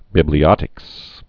(bĭblē-ŏtĭks)